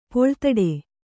♪ poḷtaḍe